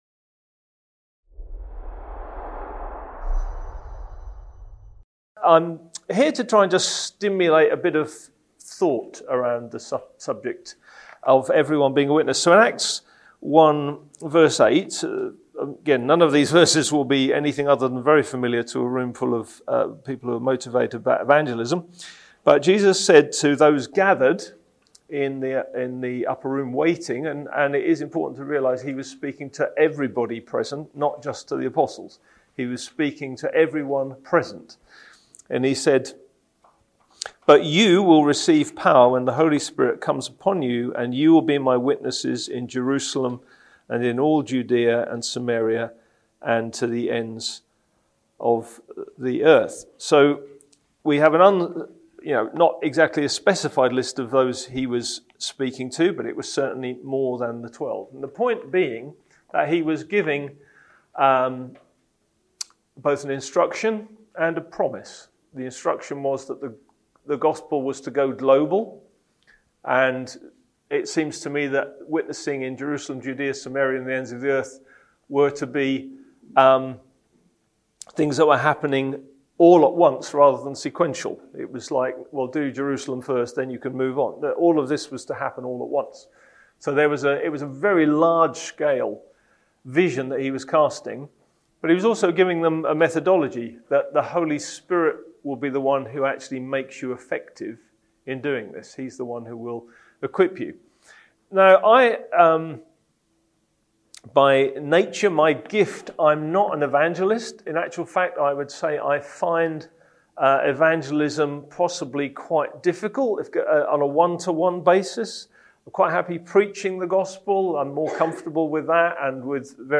The need for sharing the Gospel is huge; the fields are ripe, but how do we find labourers to engage in this vast mission? In this talk, we look at the biblical call for each of us to be a witness for Christ.